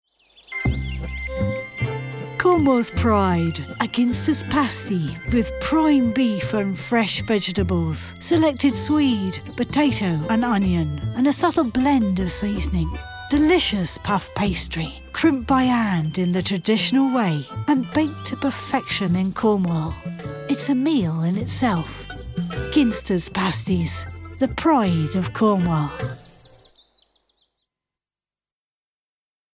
Female
Her corporate reads are professional and compelling. Narrations are delivered in clear and smooth tones.
Her USP is her native West Country accent.
Corporate
Words that describe my voice are reassuring, relatable, sophisticated.